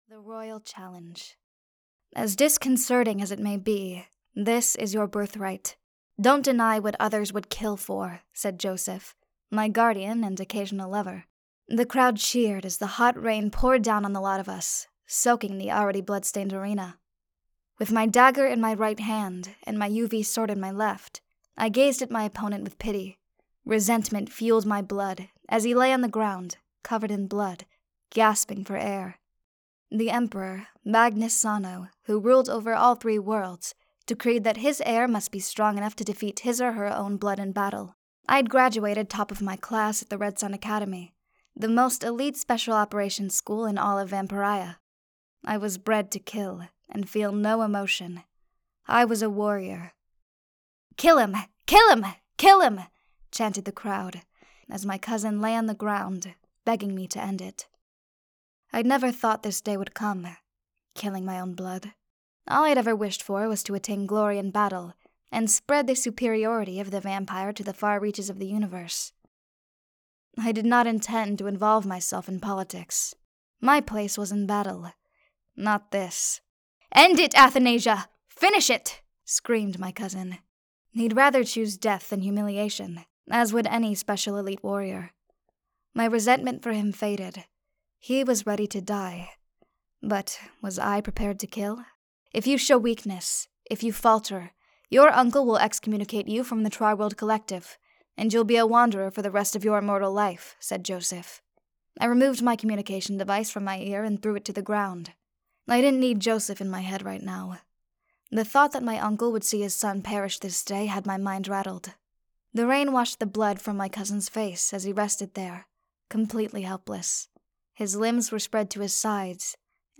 The Vampyre | Audiobook (The Rule of Three Book 2)